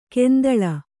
♪ kendaḷa